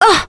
Lewsia_B-Vox_Damage_01.wav